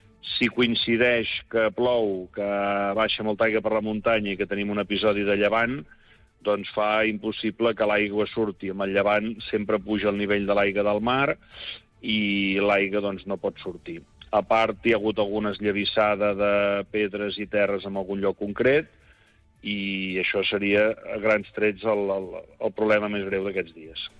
En una entrevista al Supermatí, Genís Dalmau, president de l’Entitat Municipal Descentralitzada (EMD) de l’Estartit, ha fet balanç de les afectacions del temporal Harry, que ha colpejat amb força la comarca del Baix Empordà.